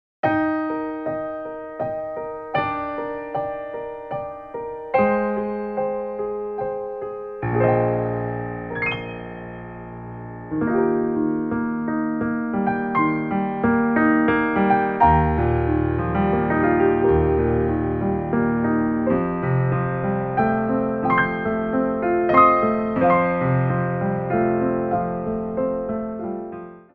Adagio
3/4 (8x8)